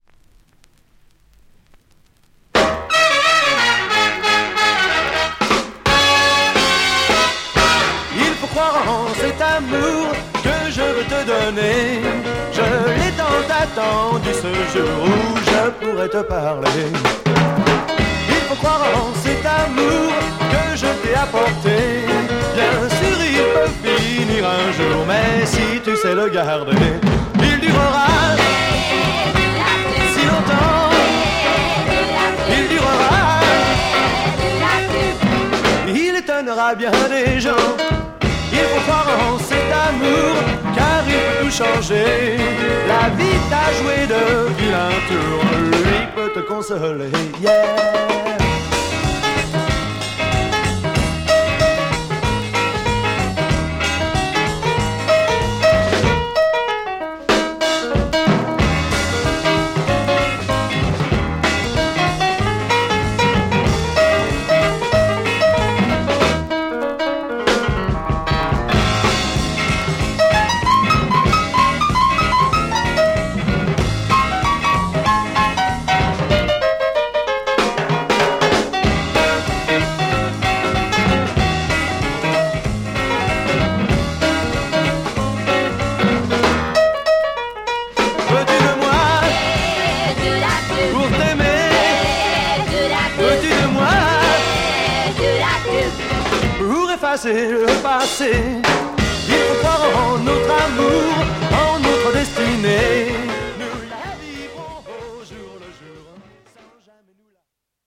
French freakbeat Mod Album
play a cool Mod freakbeat soul sound!